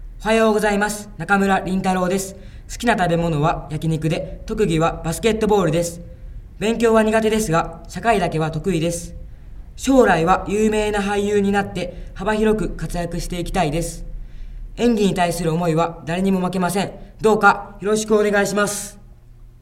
出⾝地・⽅⾔ 兵庫県・関西弁
ボイスサンプル